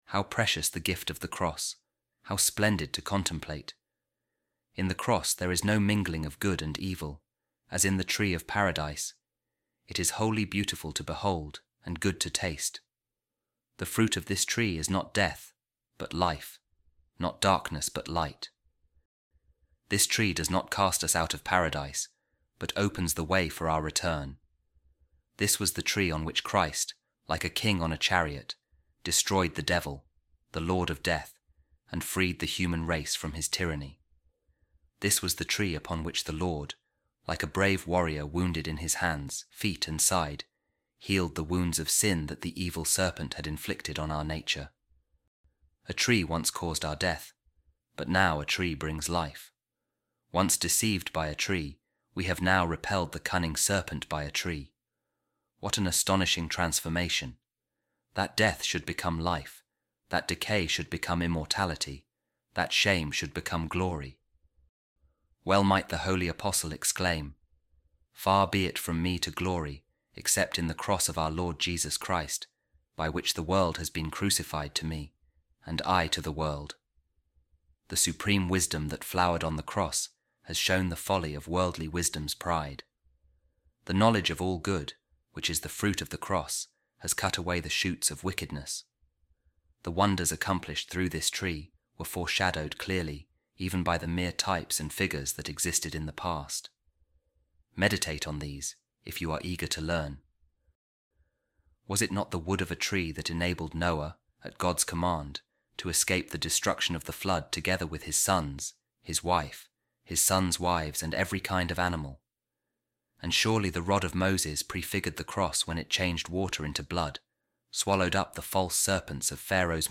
A Reading From The Addresses Of Saint Theodore The Studite